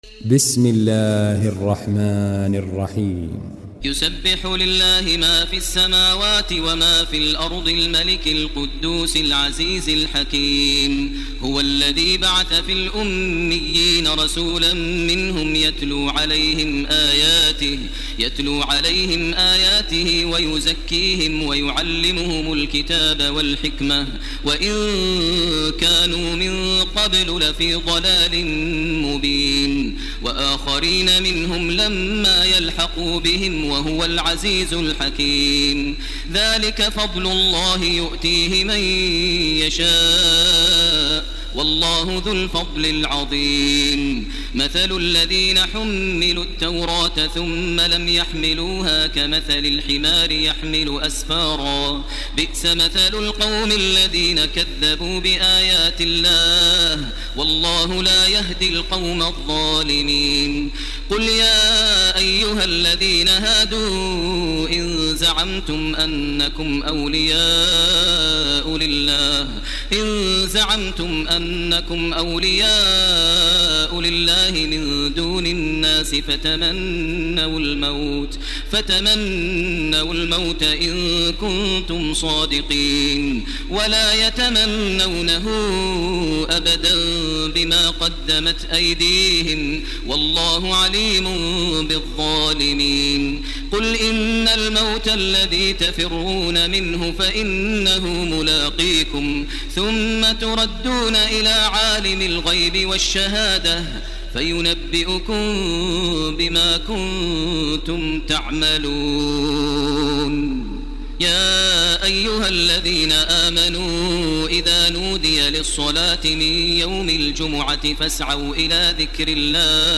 تراويح ليلة 27 رمضان 1430هـ من سورة الجمعة الى التحريم Taraweeh 27 st night Ramadan 1430H from Surah Al-Jumu'a to At-Tahrim > تراويح الحرم المكي عام 1430 🕋 > التراويح - تلاوات الحرمين